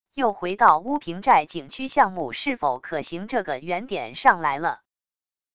又回到乌坪寨景区项目是否可行这个原点上来了wav音频生成系统WAV Audio Player